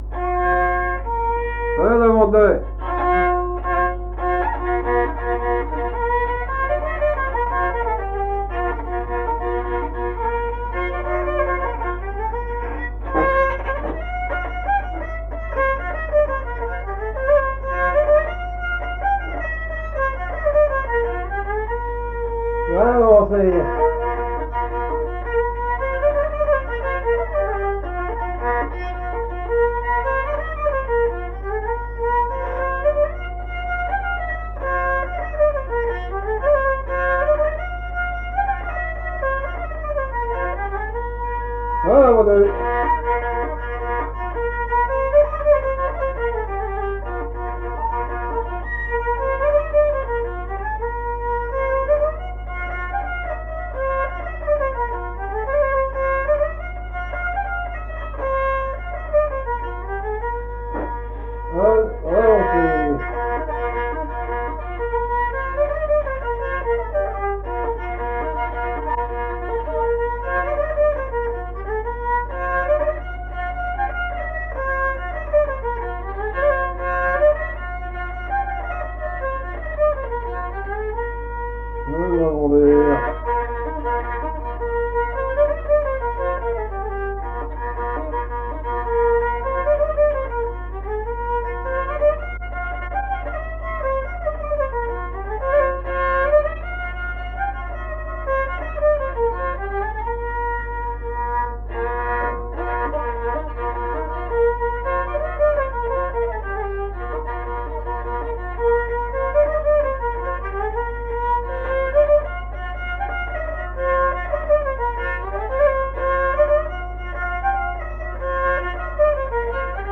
danse : branle : avant-deux ;
Répertoire de musique traditionnelle
Pièce musicale inédite